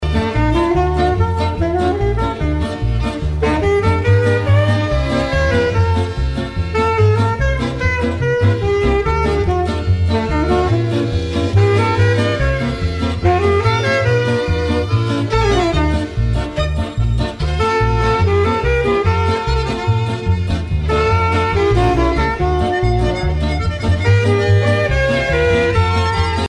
danse : hora (Israël)
Pièce musicale éditée